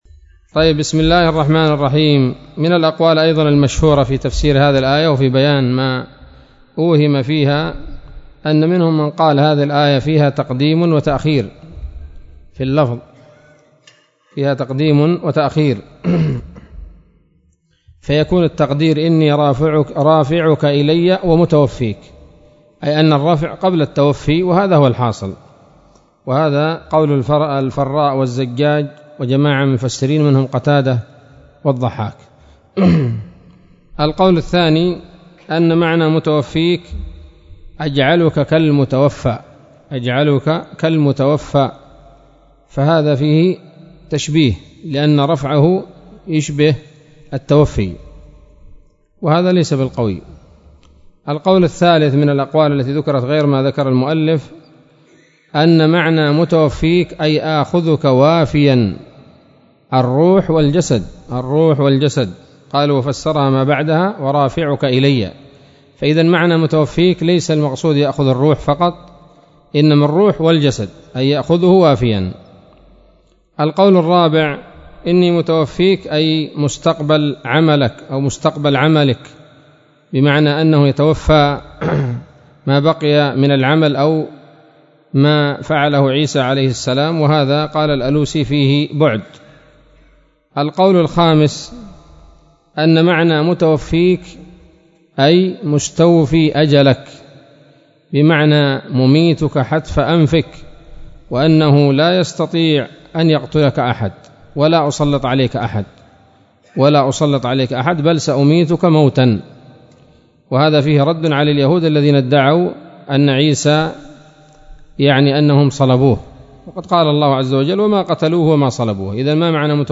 الدرس الثالث والعشرون من دفع إيهام الاضطراب عن آيات الكتاب